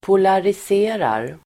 Ladda ner uttalet
Uttal: [polaris'e:rar]
polariserar.mp3